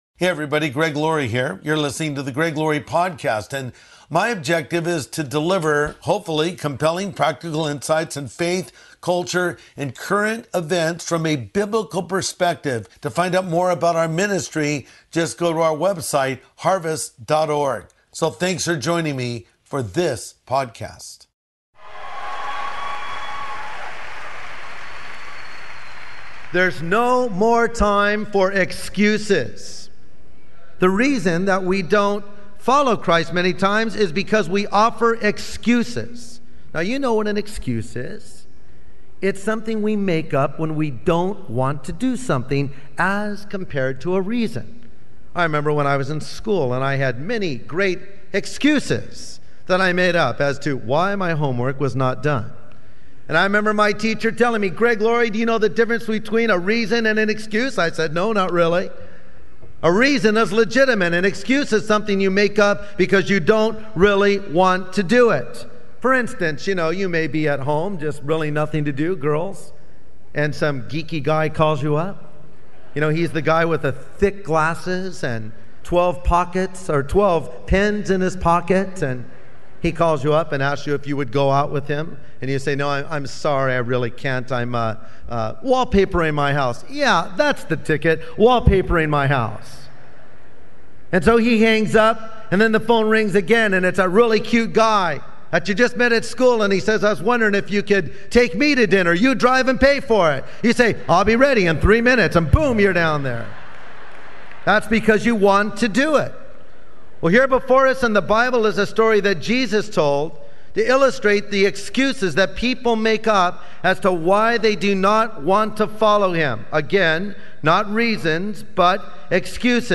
The First Harvest Crusade in 1990 | Classic Crusade